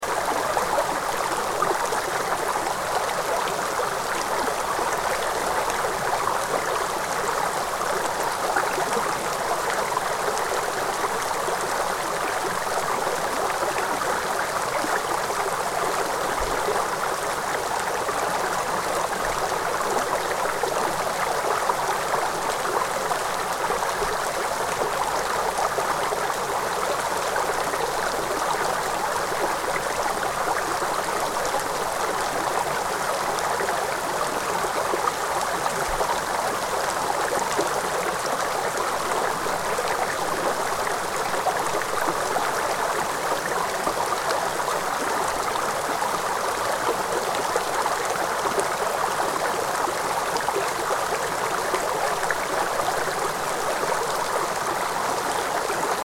Gentle Stream With Falling Sleet – Soothing Nature Sound
Relax and unwind with the soothing sound of a forest stream flowing gently through nature. Soft sleet and snowflakes fall on the water, trees, and dry leaves, creating a peaceful and immersive winter atmosphere. Enjoy this calming nature loop as a relaxing background sound for meditation, sleep, study, or stress relief.
Genres: Sound Effects
Gentle-stream-with-falling-sleet-soothing-nature-sound.mp3